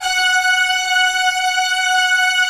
Index of /90_sSampleCDs/Optical Media International - Sonic Images Library/SI1_Fast Strings/SI1_Not Fast